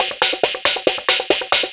DS 138-BPM B6.wav